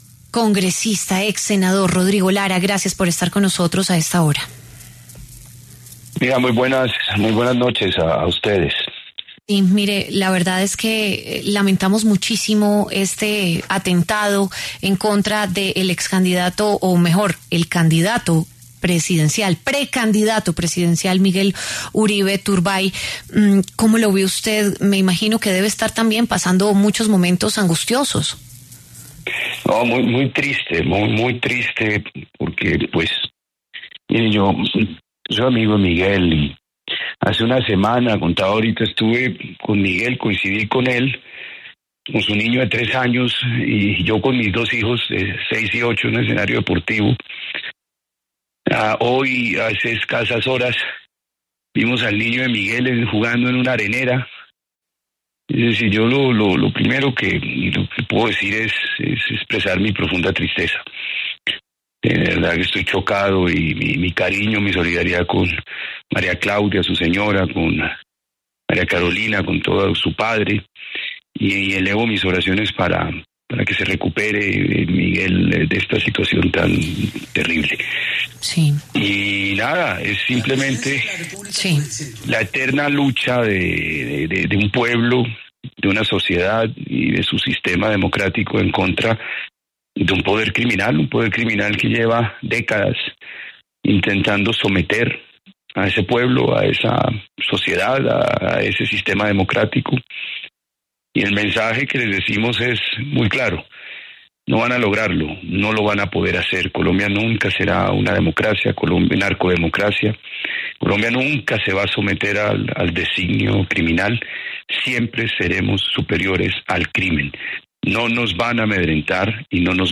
El excongresista Rodrigo Lara pasó por los micrófonos de W Radio en donde rechazó el atentado que sufrió el precandidato presidencial Miguel Uribe en la tarde de este sábado.